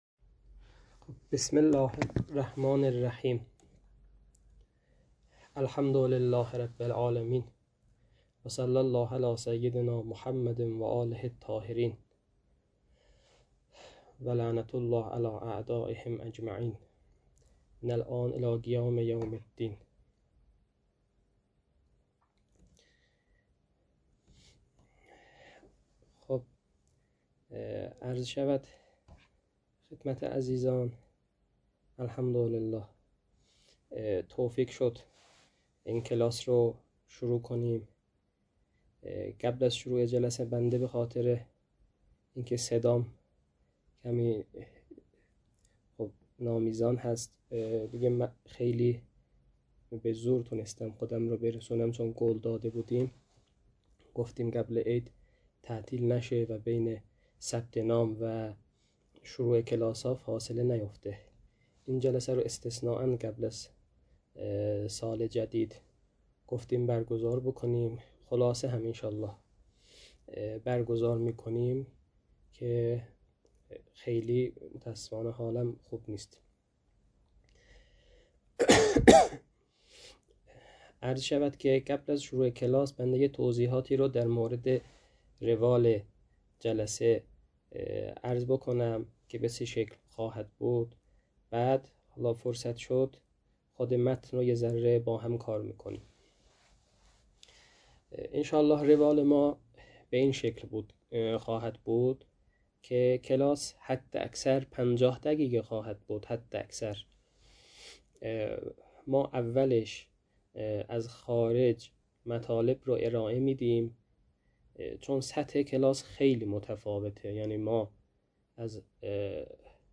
تدریس كتاب حلقه ثانیه متعلق به شهید صدر رحمه الله